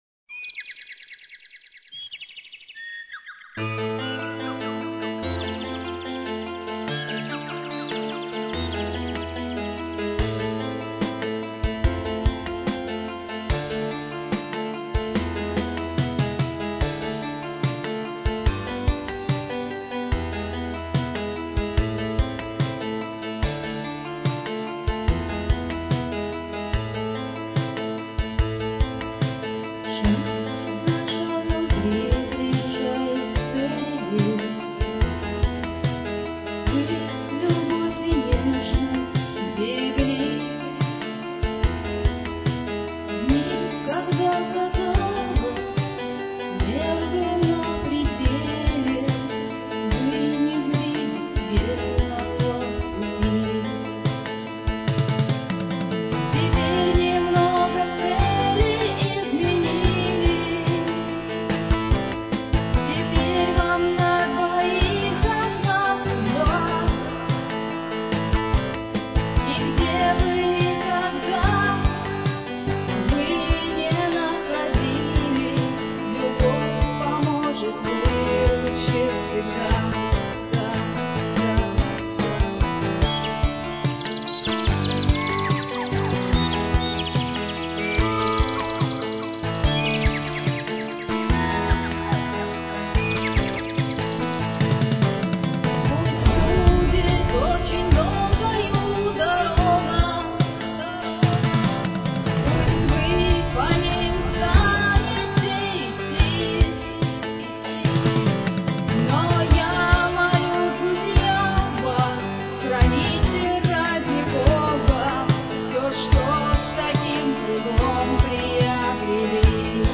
Эта песня была написана ко дню свадьбы друзей.